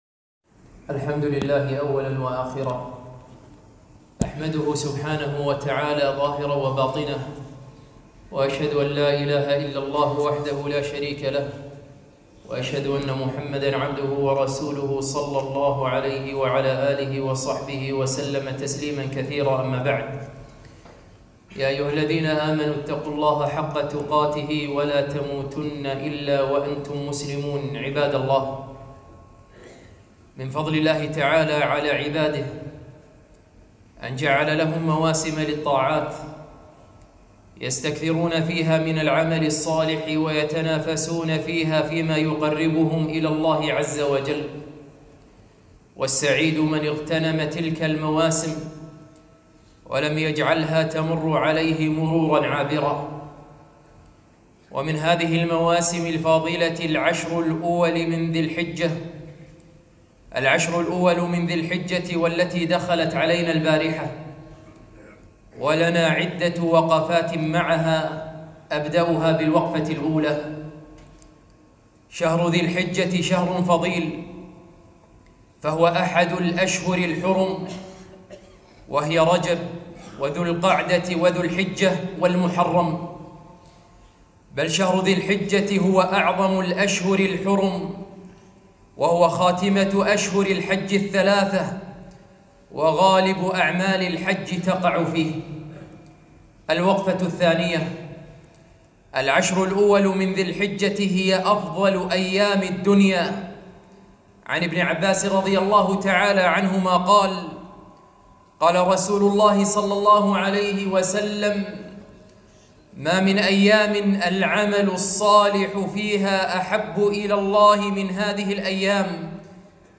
خطبة - إحدى عشرة وقفة مع عشر ذي الحجة